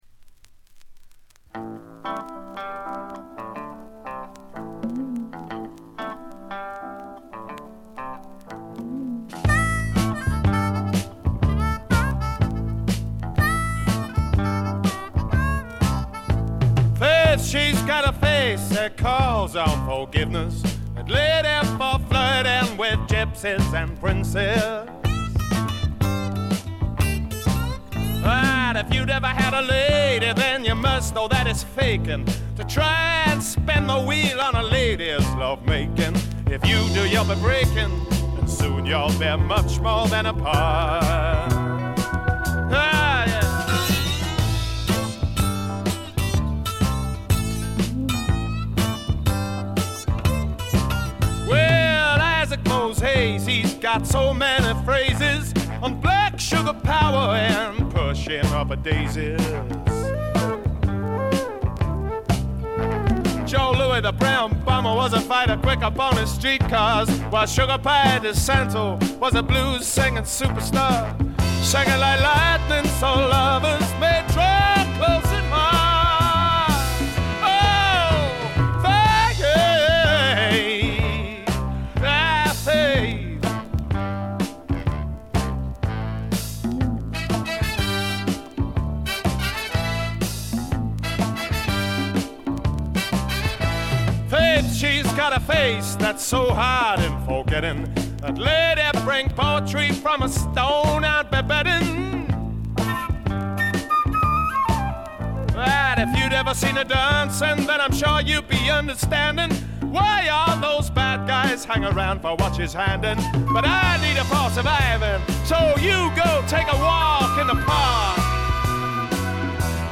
バックグラウンドノイズに軽微なチリプチ。
メランコリックで屈折した英国的翳りが底を流れているところが本作の最大の魅力かな？
試聴曲は現品からの取り込み音源です。